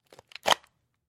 Звуки отвертки
Звук, когда отвертку взяли в руку из пенала с инструментами